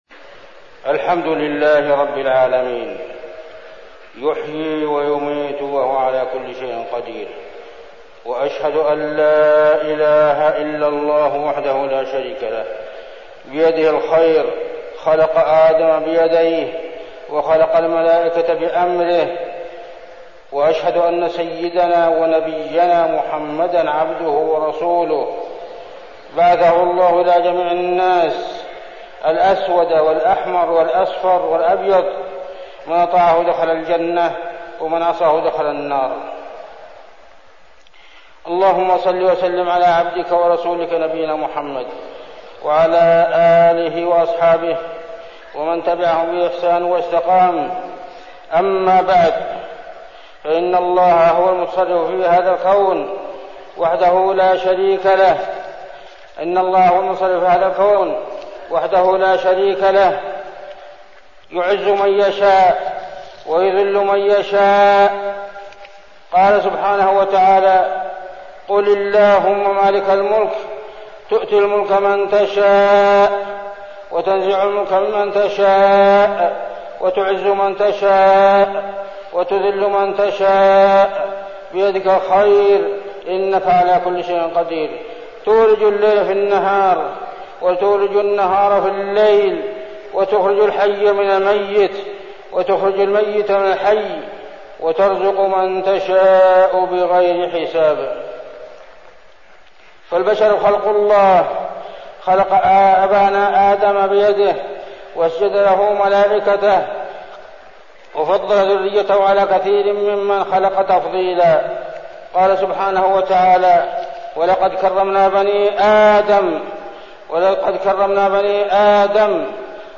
تاريخ النشر ١ صفر ١٤١٨ المكان: المسجد النبوي الشيخ